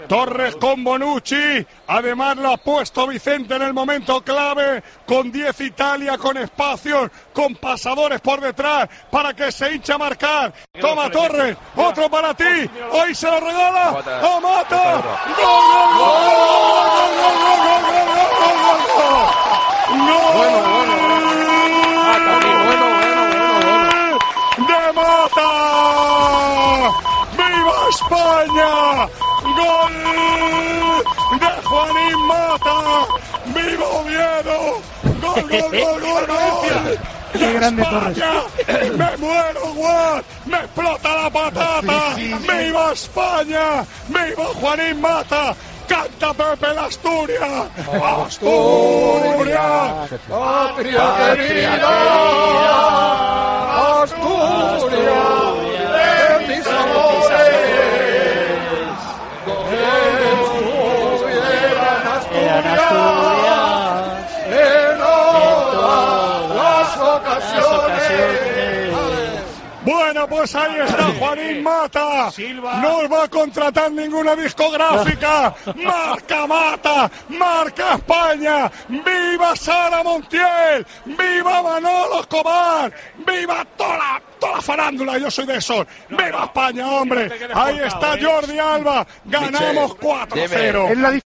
La Eurocopa de 2012 fue la última vez que España levantó un gran título internacional, y lo escuchamos con inconfundible voz de Manolo Lama